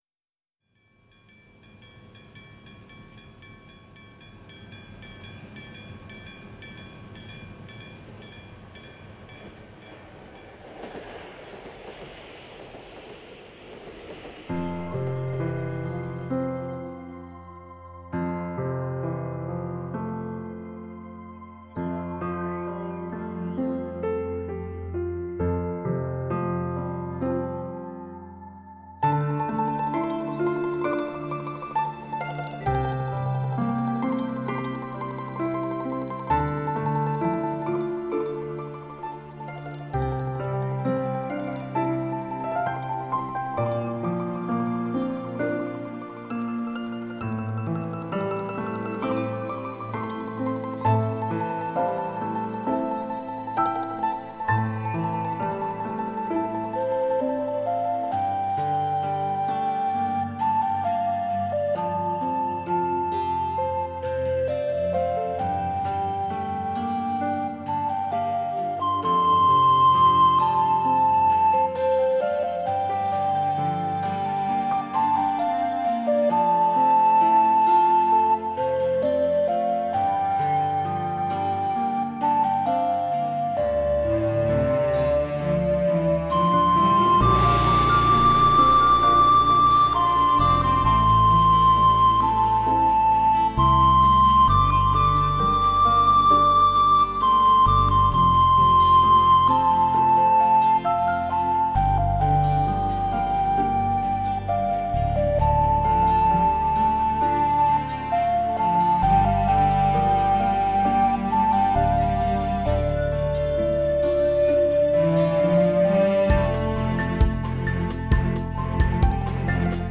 dts 5.1声道音乐
古筝、琵琶等中乐器及西方弦乐，
由众多顶尖乐手联袂演奏
由高水准的专业录音器材老牌德国胆咪